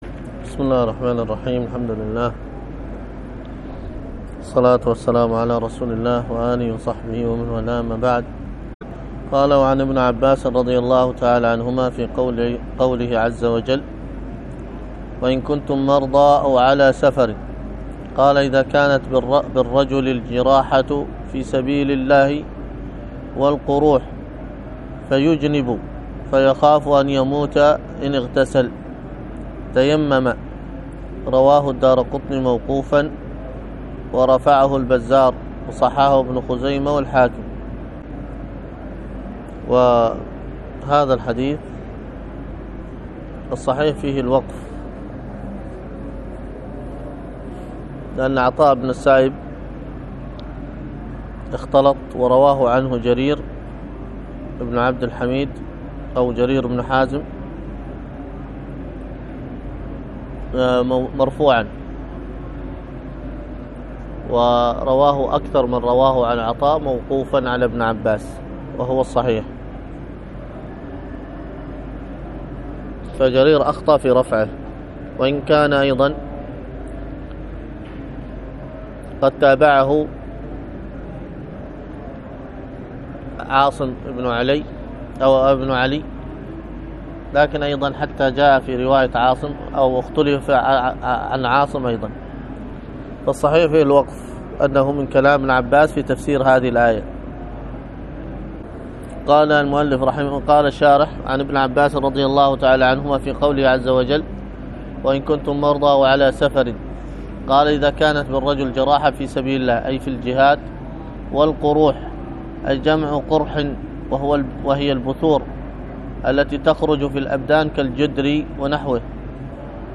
الدرس في التعليق على مقدمة سنن الدارمي 69، ألقاها